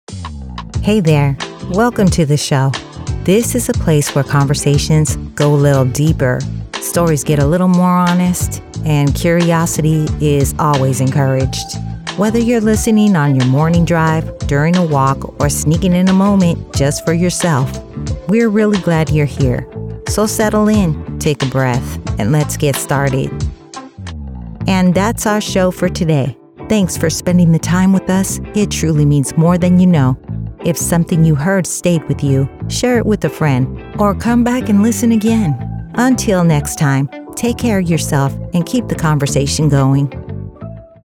Podcast Voice Overs
Adult (30-50)